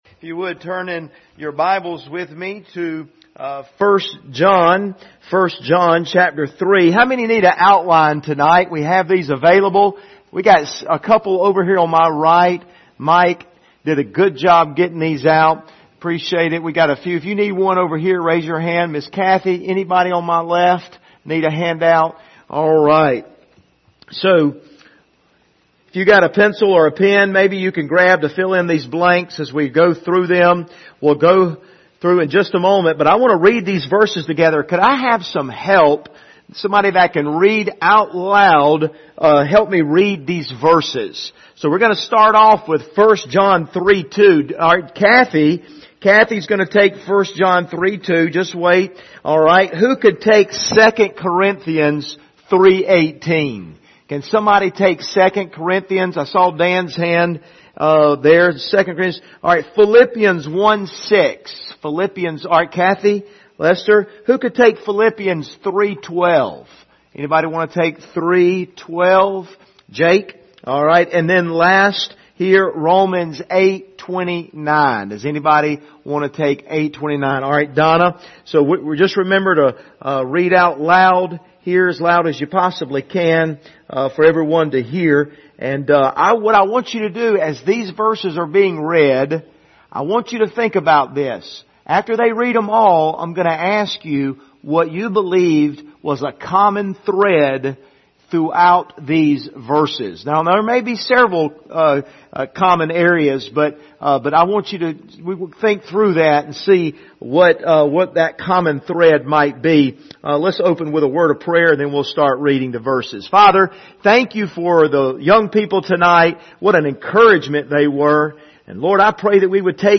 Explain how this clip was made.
Service Type: Wednesday Evening Topics: sanctification